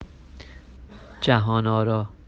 The second is an Iranian friend: